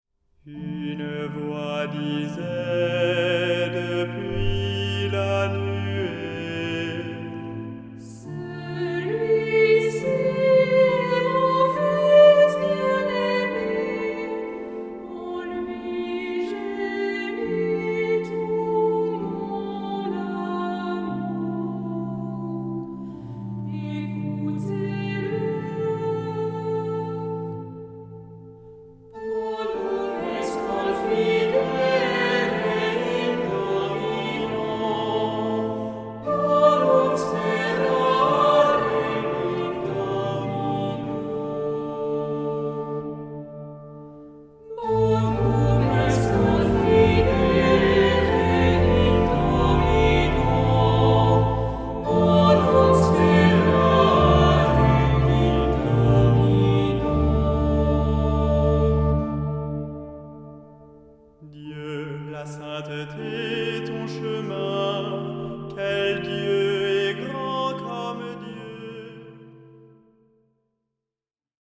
Genre-Style-Form: troparium ; Psalmody ; Sacred
Mood of the piece: collected
Type of Choir: SATB  (4 mixed voices )
Instruments: Organ (1)
Tonality: D minor